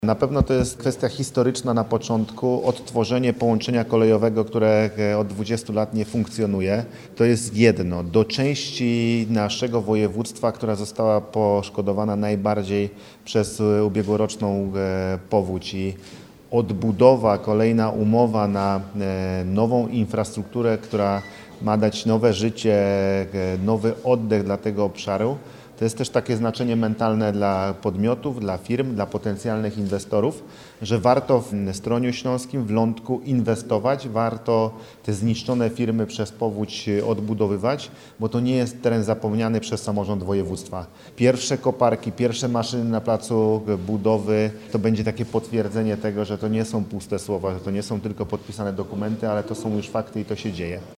– To strategiczne połączenie, które ma ogromne znaczenie dla turystyki, lokalnej gospodarki oraz mieszkańców terenów dotkniętych ubiegłoroczną powodzią – podkreśla Paweł Gancarz, Marszałek Województwa Dolnośląskiego.